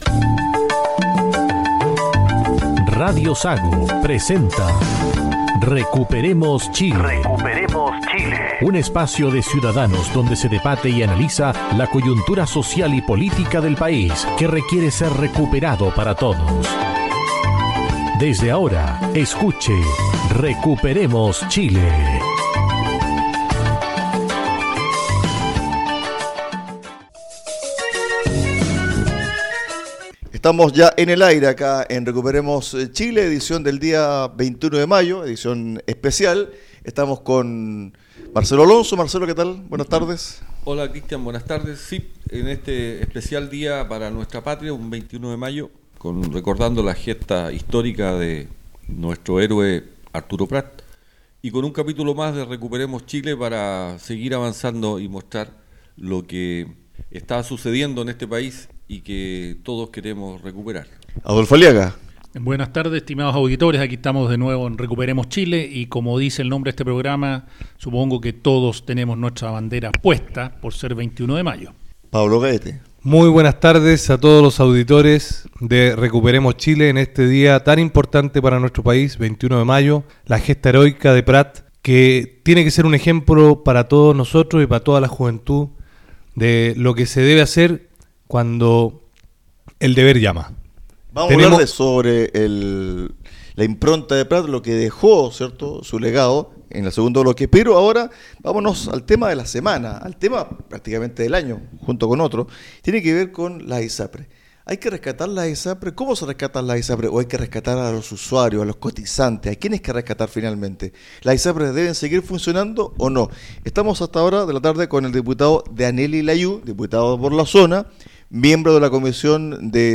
En este capítulos los panelistas abordan la crisis de las Isapres junto a las vías para salvar a los cotizantes e impedir un colapso sanitario en el país.
En este tema participa como invitado el diputado UDI, Daniel Lilayú, quien es miembro de la comisión de Salud de la Cámara Baja. Además, se analizó la escalada de violencia de los grupos terroristas en la Macrozona Sur y el repaso de la gesta heroica de Arturo Prat.